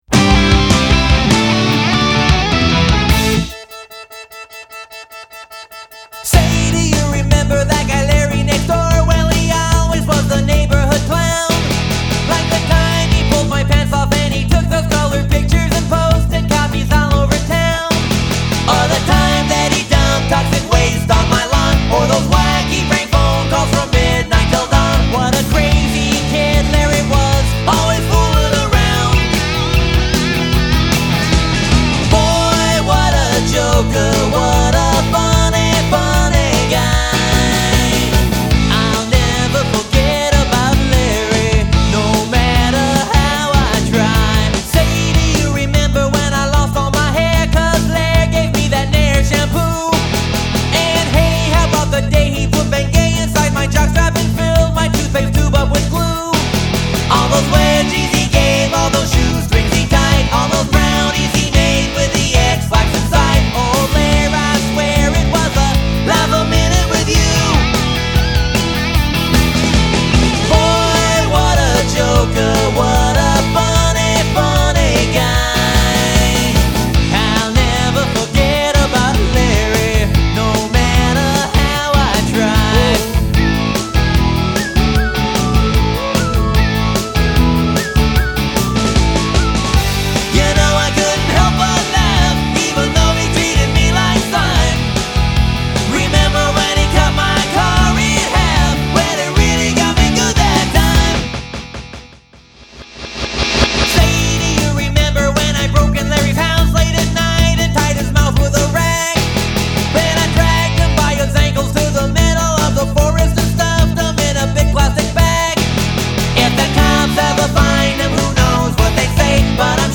Tags: Funny Comedy Rock Music Cool adult